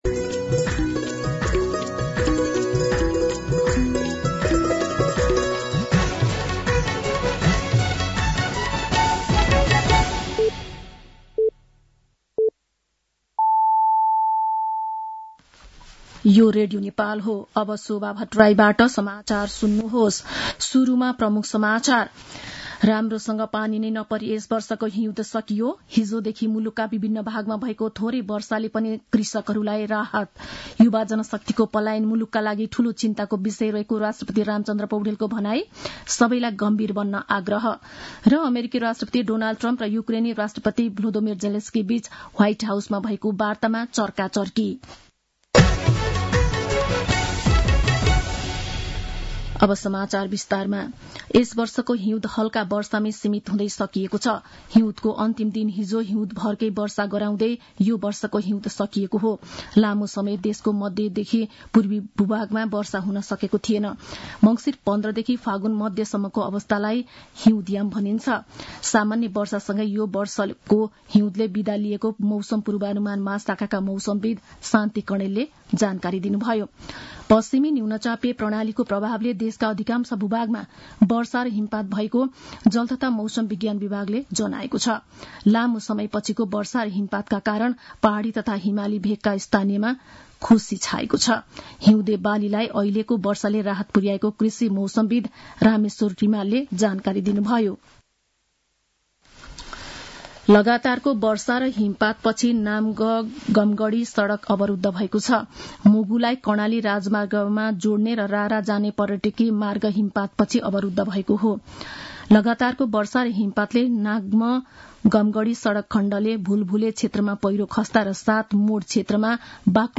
दिउँसो ३ बजेको नेपाली समाचार : १८ फागुन , २०८१